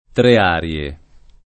Trearie [ tre # r L e ]